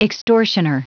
Prononciation du mot extortioner en anglais (fichier audio)
Prononciation du mot : extortioner